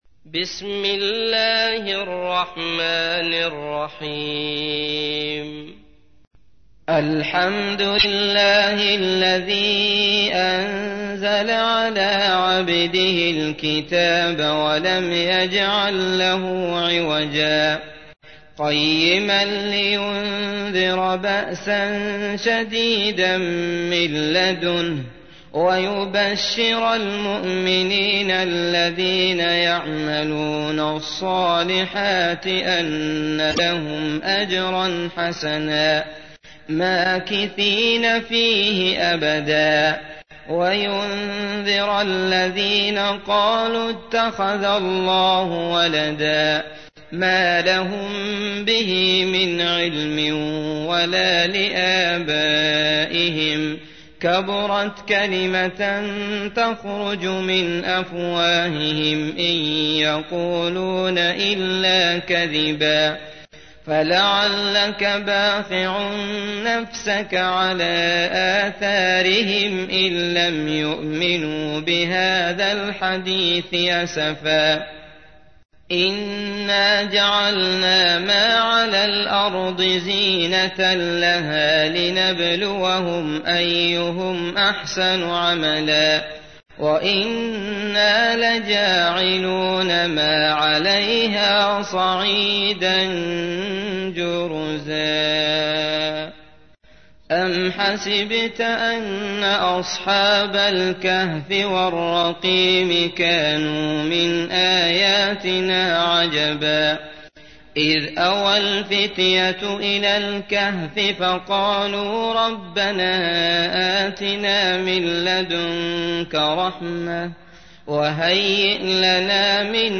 تحميل : 18. سورة الكهف / القارئ عبد الله المطرود / القرآن الكريم / موقع يا حسين